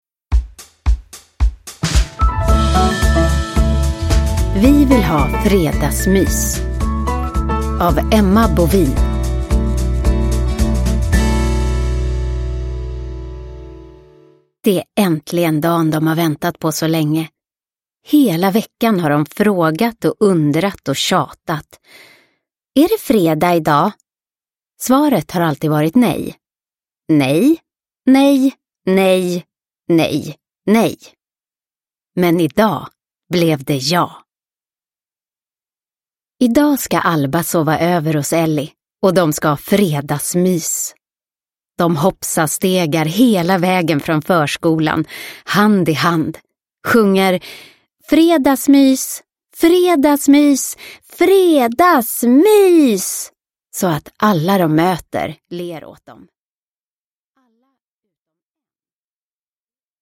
Vi vill ha fredagsmys! – Ljudbok – Laddas ner